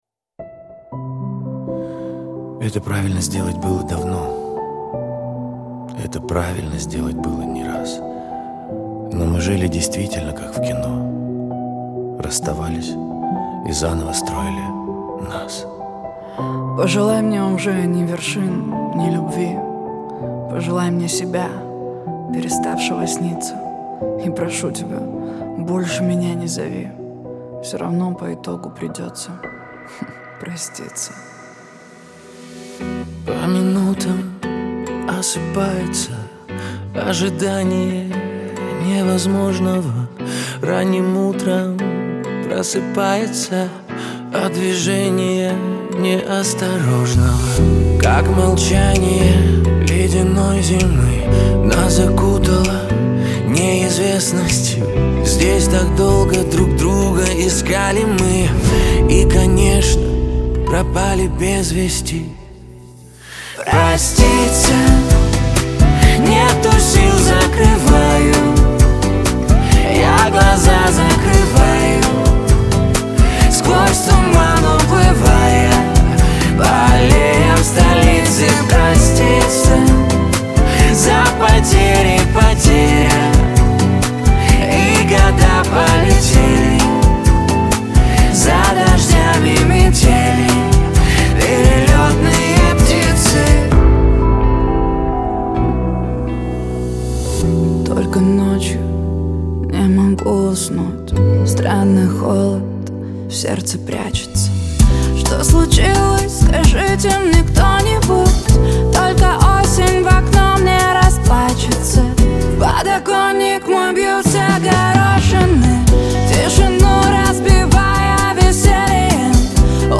Трек размещён в разделе Русские песни / Поп.